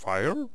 archer_select9.wav